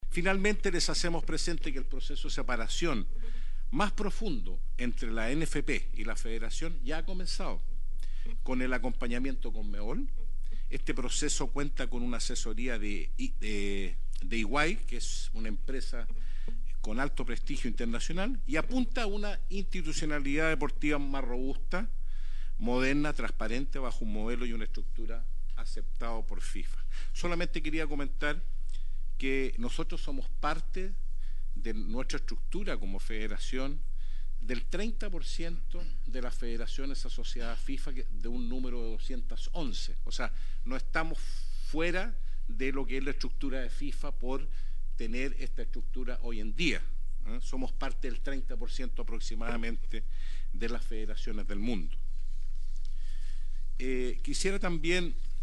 El anuncio se dio en una sesión de la Comisión de Constitución del Senado, donde se discute el proyecto que regula las sociedades anónimas deportivas profesionales, en materia de fiscalización, de conflictos de interés, y de fomento de la participación de los hinchas en la propiedad de las mismas.